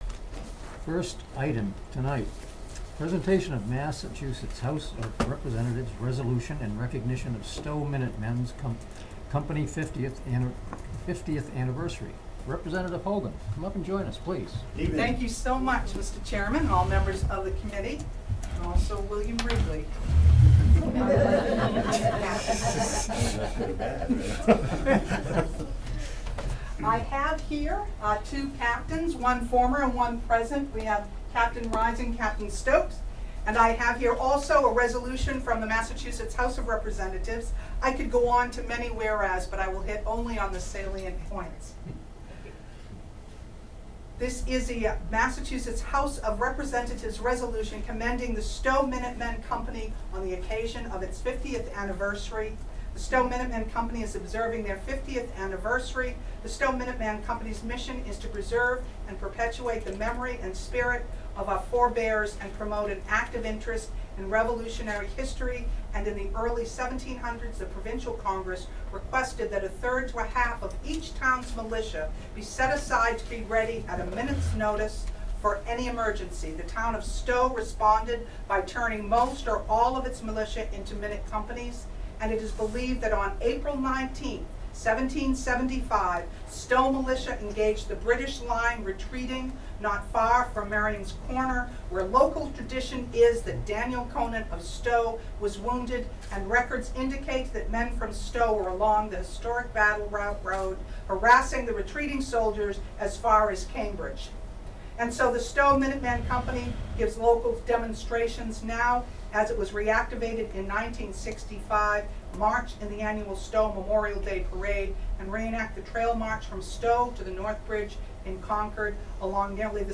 The Stow Minutemen Company receives recognition at a Stow Board of Selectmen meeting.